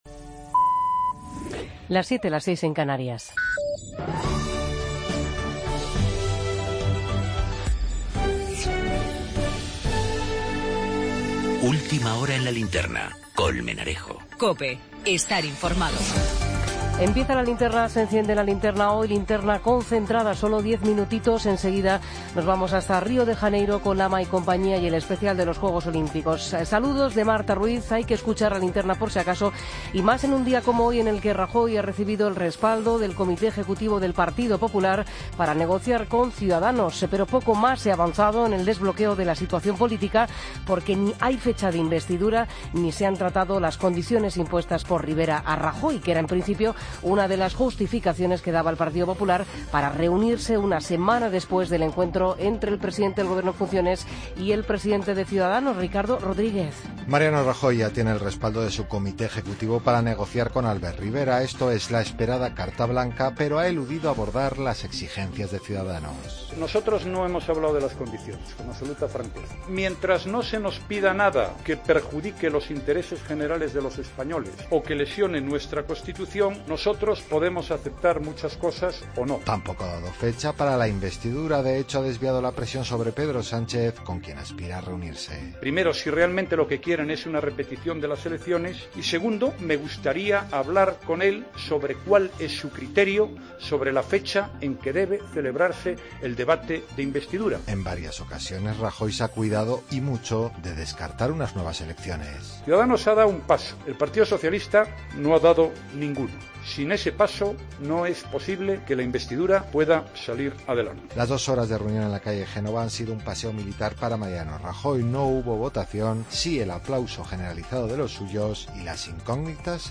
Actualización informativa, miércoles 17 de agosto de 2016